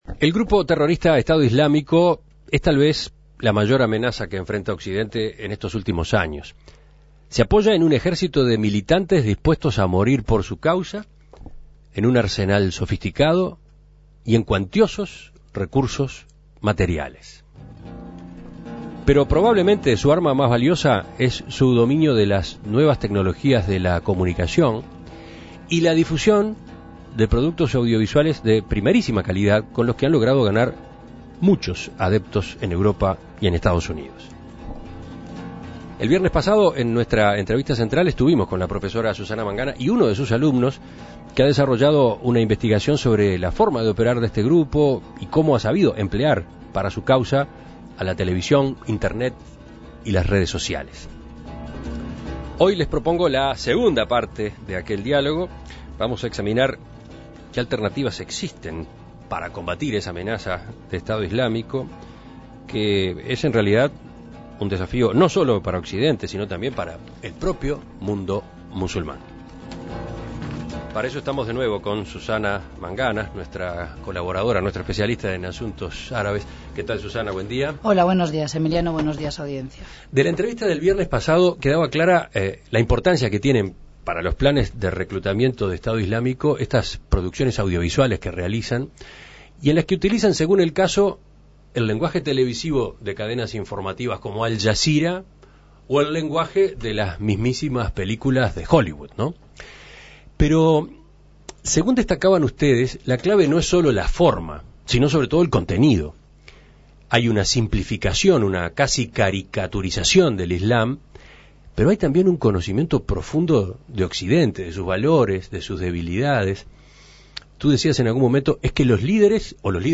recibió nuevamente en estudios